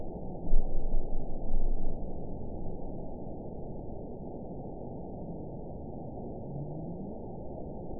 event 922147 date 12/27/24 time 11:58:06 GMT (11 months, 1 week ago) score 9.63 location TSS-AB06 detected by nrw target species NRW annotations +NRW Spectrogram: Frequency (kHz) vs. Time (s) audio not available .wav